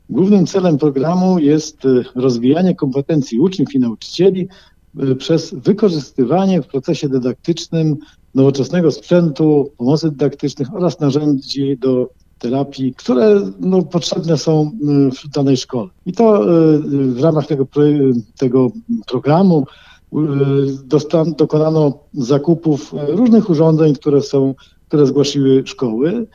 Marek Chojnowski – starosta ełcki.